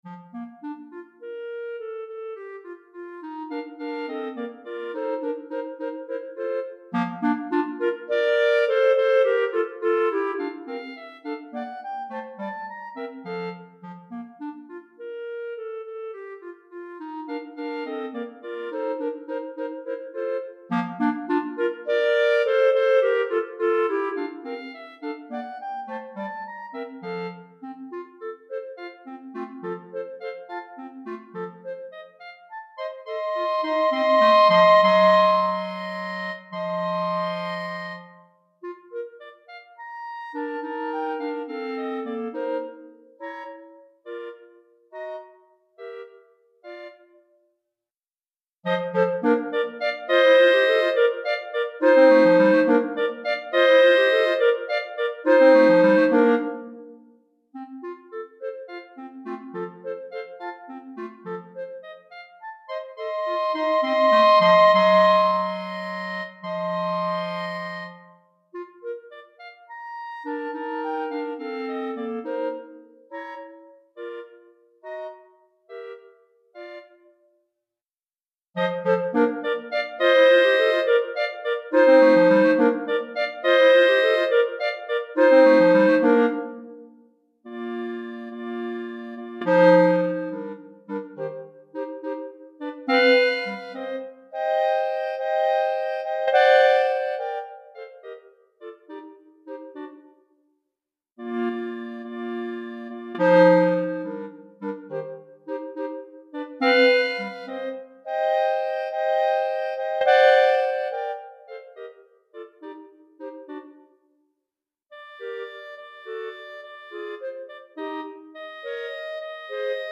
3 Clarinettes